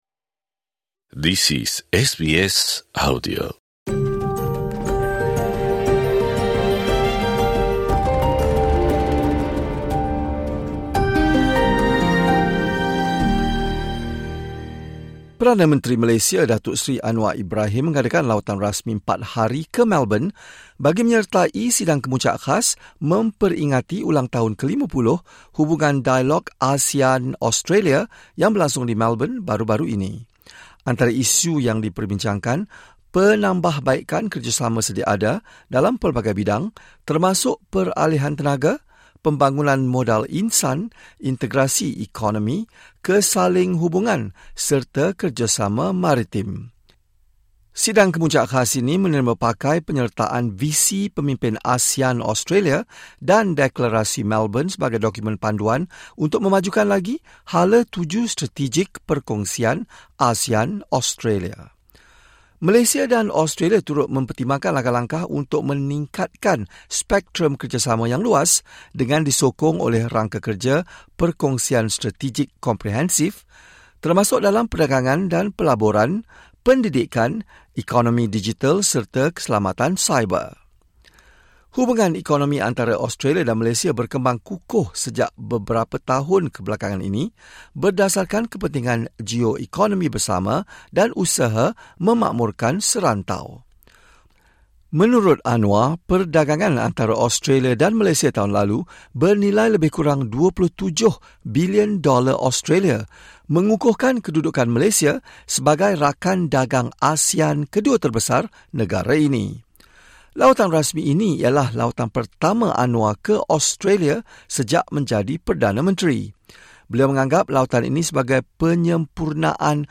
Perdana Menteri Australia, Anthony Albanese, mengadakan perbincangan dengan Perdana Menteri Malaysia, Anwar Ibrahim, sempena sidang kemuncak khas ASEAN-Australia 2024 di Melbourne. Dalam sidang media, pemimpin kedua-dua buah negara menyatakan hasrat untuk terus memperkukuhkan lagi perhubungan dua-hala di antara Australia dan Malaysia. Albanese turut menyatakan minat Australia untuk lebih akrab dengan pertubuhan ASEAN dan memuji Malaysia sebagai antara rakan perdagangan Australia paling penting di rantau Asia.